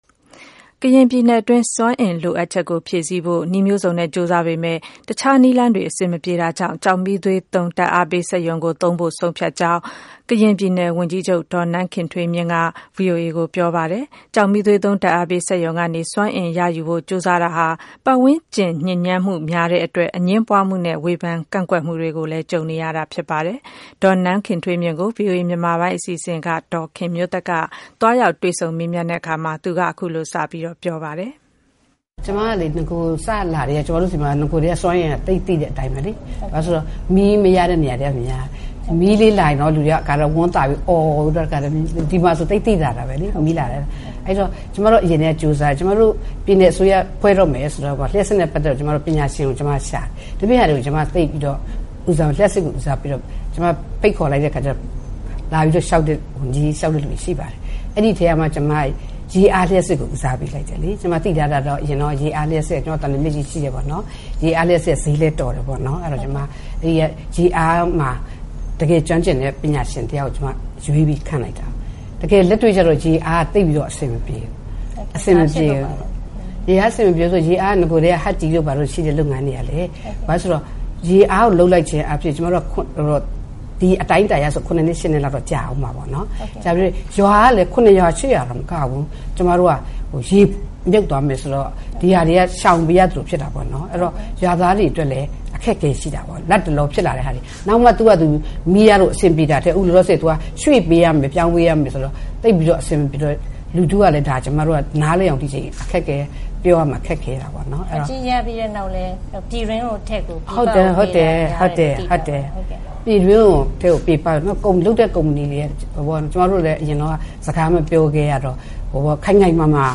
တွေ့ဆုံမေးမြန်း